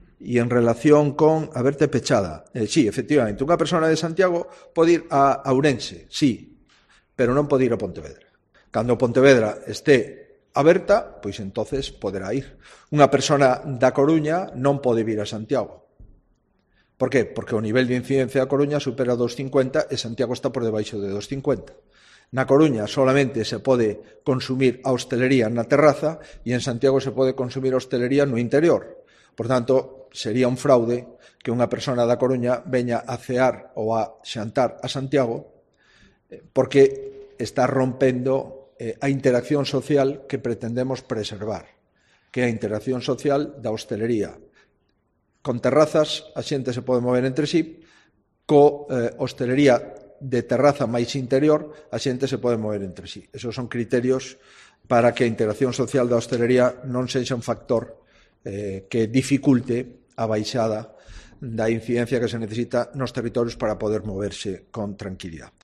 Declaraciones de Núñez Feijóo sobre las restricciones de movilidad